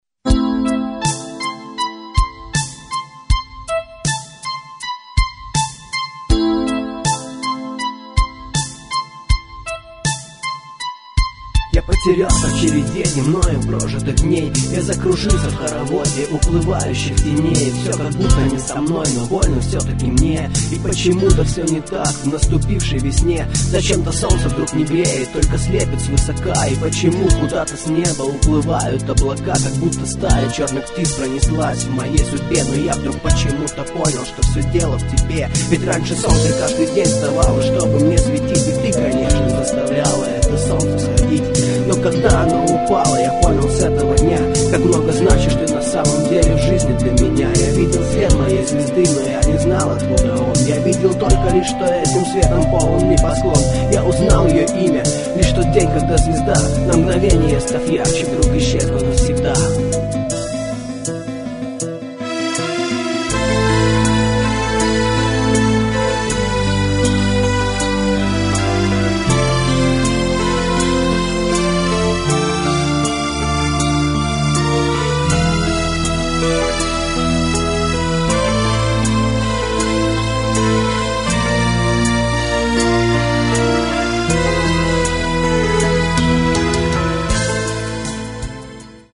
Клавиши, перкуссия, вокал
фрагмент (523 k) - mono, 48 kbps, 44 kHz